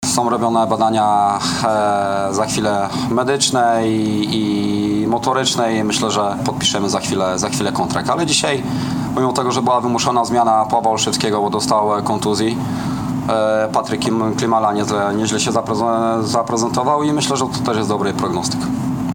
– Nasza gra była bardzo szarpana – powiedział na pomeczowej konferencji